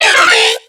Cri de Muciole dans Pokémon X et Y.